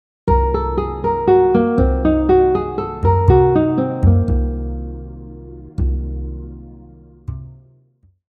Lastly, example 5 incorporates the half diminished arpeggio with added chromatic notes which gives it a hint of the blues scale. The notes in the 2nd measure outline an Eb minor blues scale with a 6th degree instead of b7.
half-diminished-arpeggio-example-5.mp3